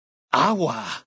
Category:Dead Horses pidgin audio samples Du kannst diese Datei nicht überschreiben.
Ouch!.ogg